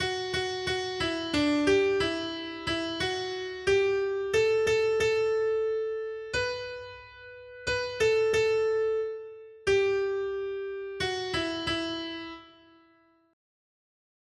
Noty Štítky, zpěvníky ol502.pdf responsoriální žalm Žaltář (Olejník) 502 Skrýt akordy R: Hospodinovým podílem je jeho lid. 1.